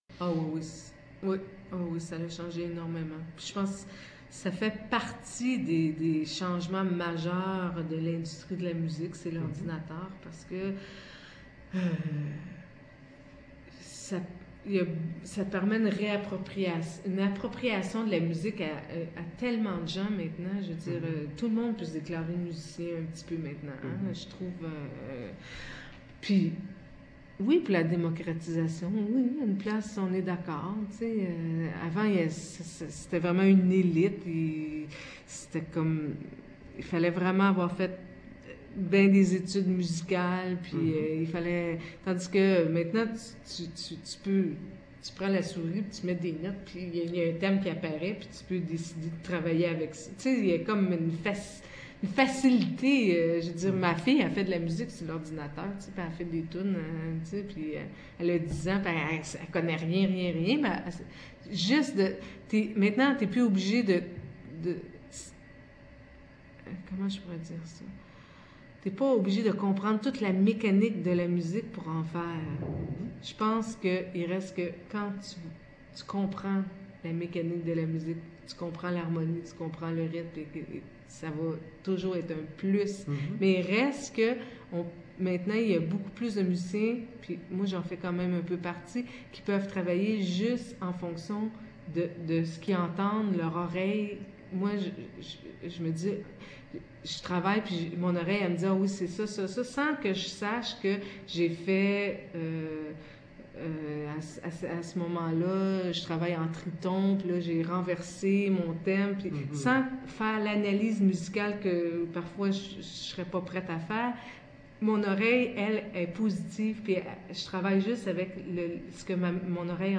Résumé de l'entrevue :